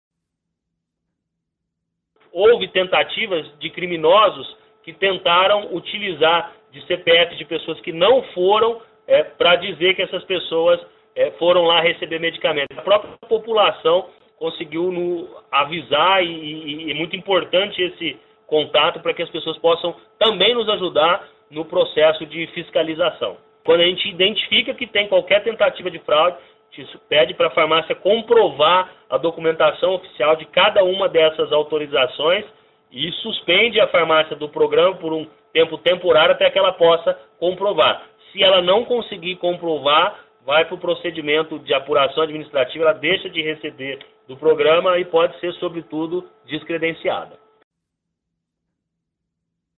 O diretor do Departamento Nacional de Auditoria do Sistema Único de Saúde (SUS), do Ministério da Saúde, Rafael Bruxellas, afirmou que cada farmácia fora dos padrões representa um risco para o acesso correto da população aos medicamentos e sua exclusão abre espaço para novos credenciamentos de estabelecimentos regulares.